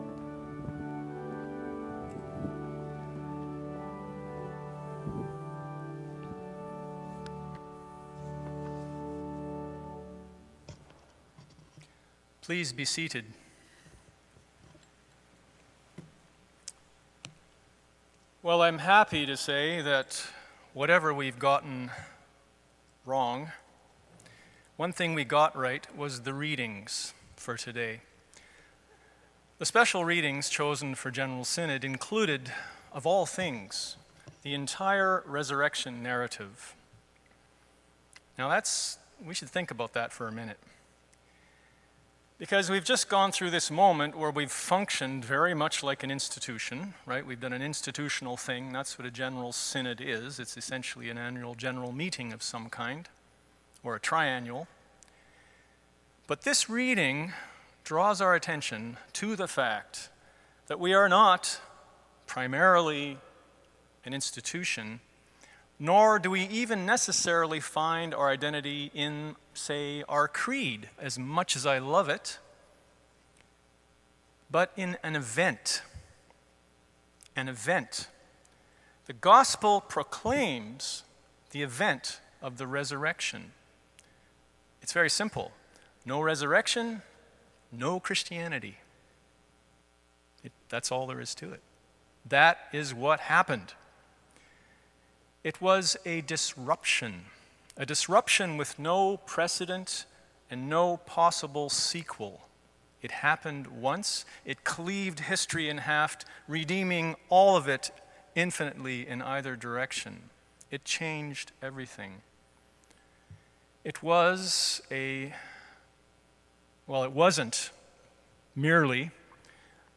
9.15 Sermon